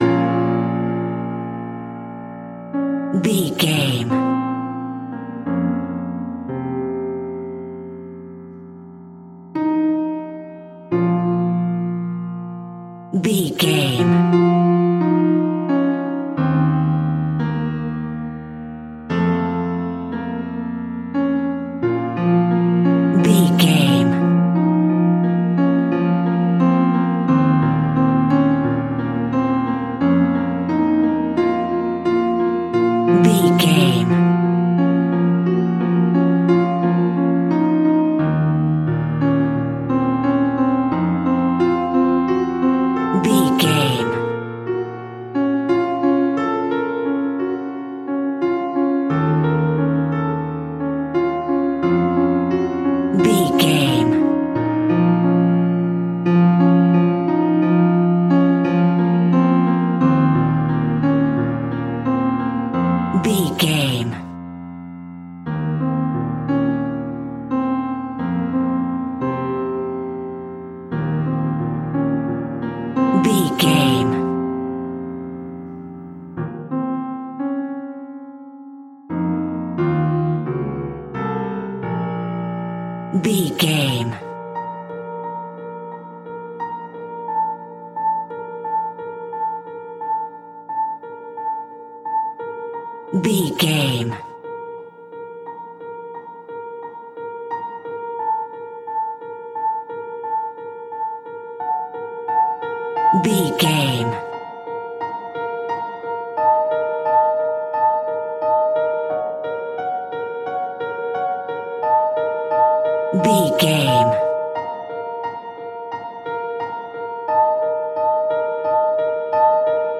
Scary Piano Suite.
Aeolian/Minor
B♭
Slow
tension
ominous
dark
eerie